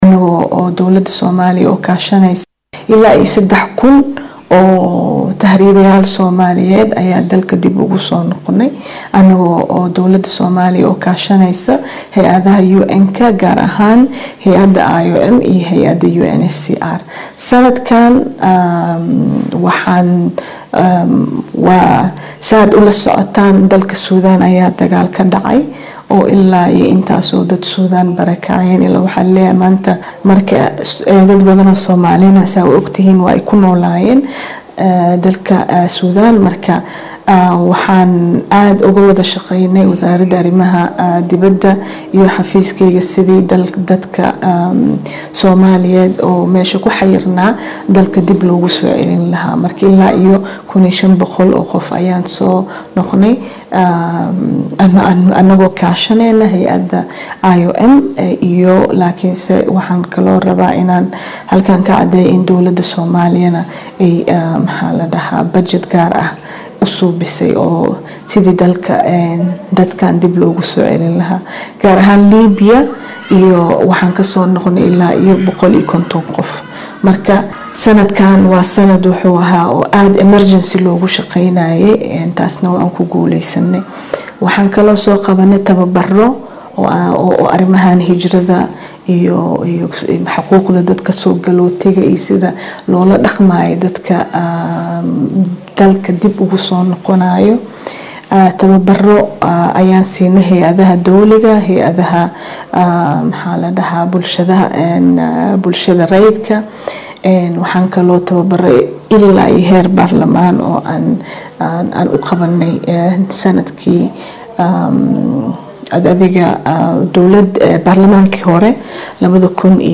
Iyadoo maanta ay ku beegan tahay maalinta caalamiga ah ee Muhaajiriinta ayaa dowladda Soomaaliyeed waxaa ay sheegtay in dalka ay ku soo celisay muwaaadiniin ka badan 3kun. Ergayga Madaxweynaha ee arrimaha Muhaajiriinta iyo caruurta Ambassador Maryam Yaasiin oo wareysi siisay talafishaanka qaraanka Soomaaliyeed ayaa sheegtay in sii waadi donaan in dalka dib loogu soo celiyo muhajiriinta soomaaliyeed ee dalalka kala duwan ee dunida.